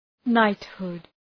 knighthood.mp3